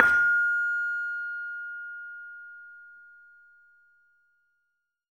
LAMEL F5  -R.wav